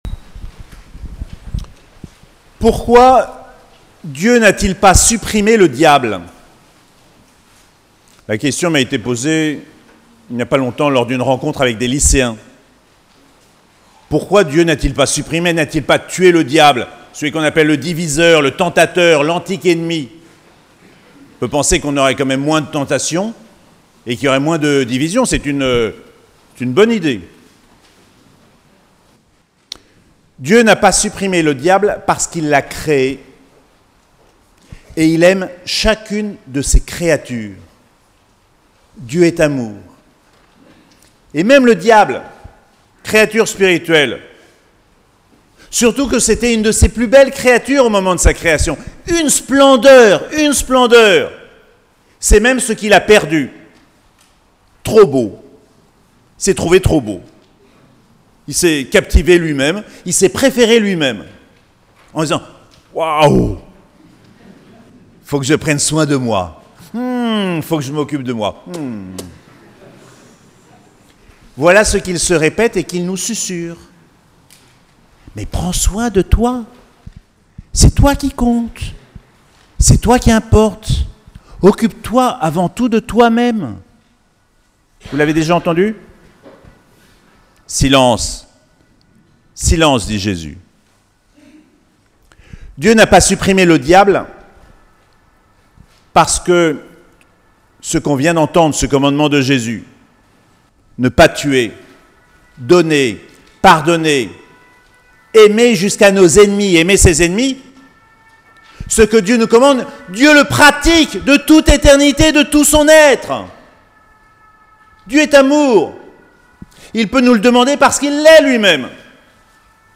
7ème dimanche du Temps Ordinaire - 20 février 2022